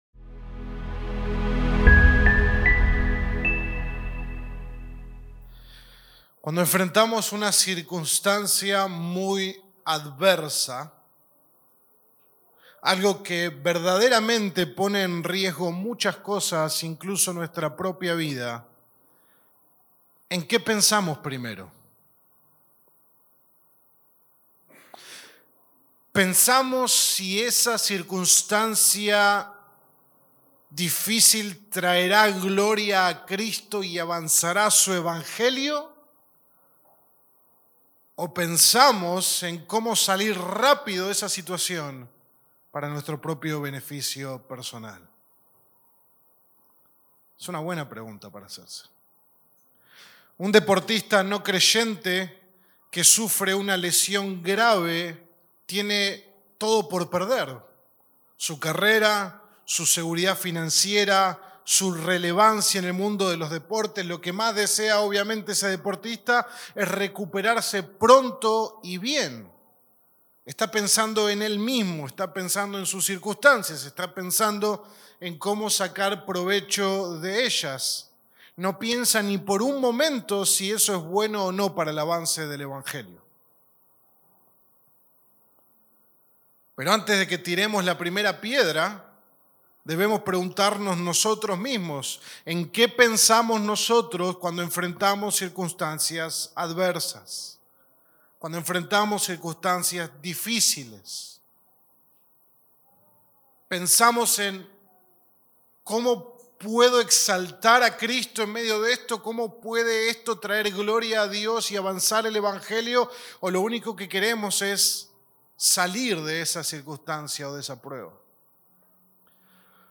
Sermón 2 de 8 en Cristo es Nuestra Vida